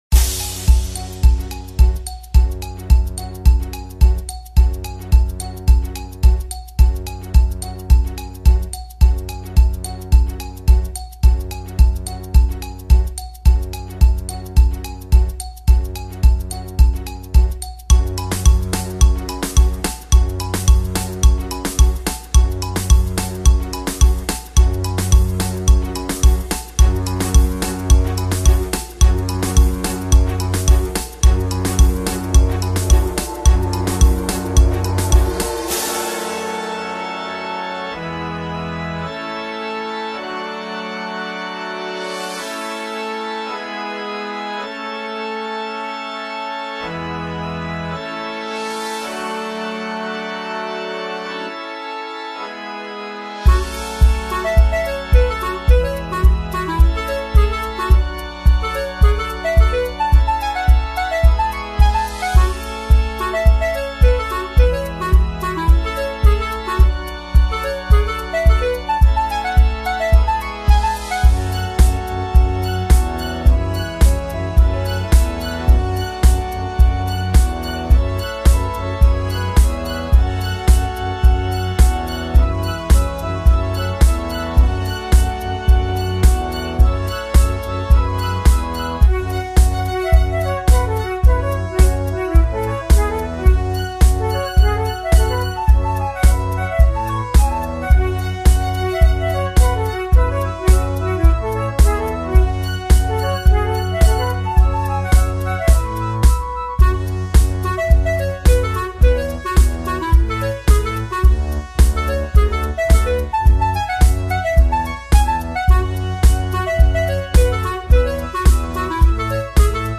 An orchestral version